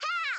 File:Ice Climbers voice sample Popo.oga
Ice_Climbers_voice_sample_Popo.oga.mp3